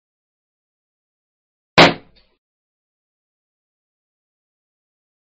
Tiếng Bóng Bay, bóng hơi Nổ bốp… nhẹ hơn
Thể loại: Tiếng đồ vật
Âm thanh này mô phỏng tiếng nổ nhẹ khi kim chọc vào bóng bay, thường thấy trong các bữa tiệc, sinh nhật hay cảnh hài hước.
tieng-bong-bay-bong-hoi-no-bop-nhe-hon-www_tiengdong_com.mp3